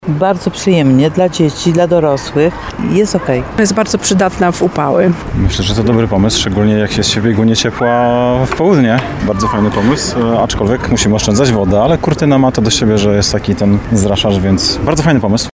Nasz reporter był na miejscu z mikrofonem.